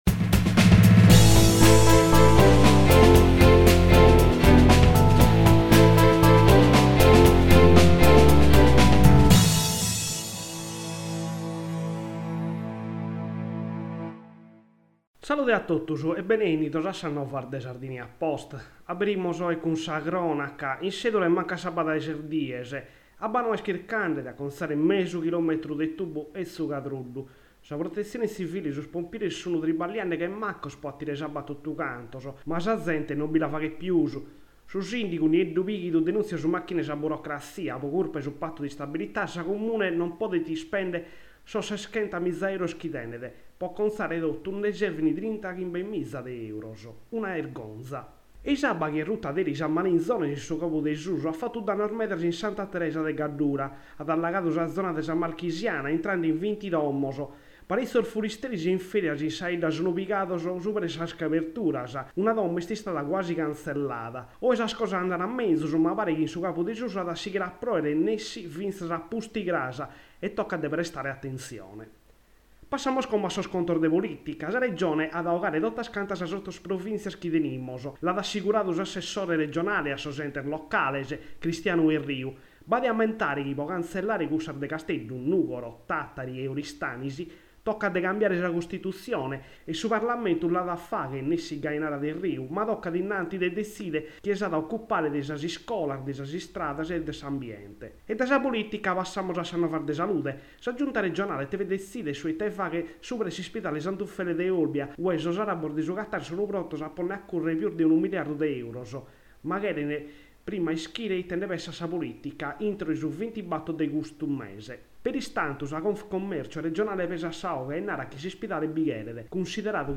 Radio novas de su 17 de lampadas